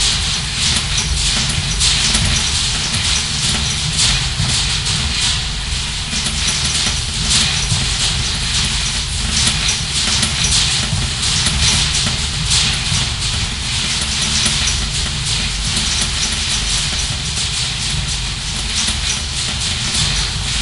rainonroof.ogg